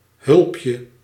Ääntäminen
Ääntäminen : IPA: [slʊɦa] Haettu sana löytyi näillä lähdekielillä: tšekki Käännös Ääninäyte 1. hulpje 2. hulp {f} 3. huishoudhulp {f} 4. bediende {c} 5. knecht {m} 6. meid {f} Suku: m .